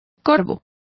Also find out how corva is pronounced correctly.